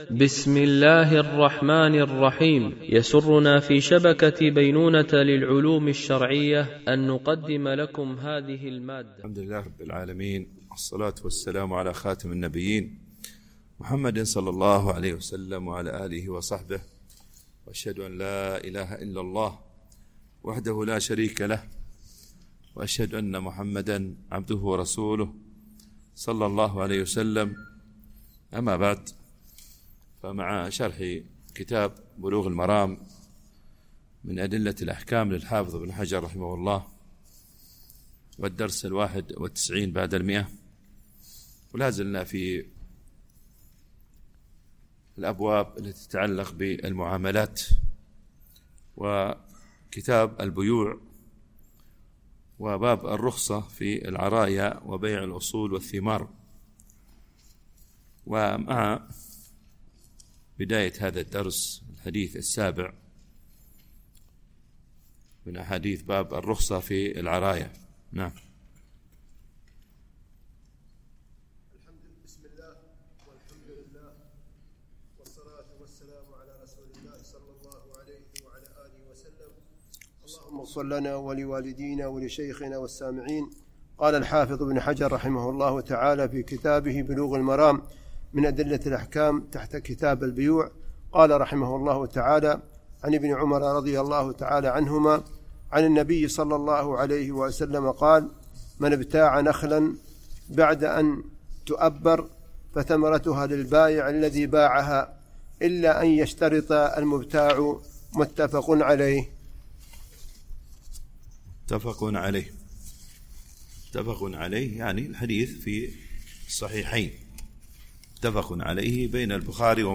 شرح بلوغ المرام من أدلة الأحكام - الدرس 191 ( كتاب البيوع - الجزء ٢٥ - الحديث 854-856 )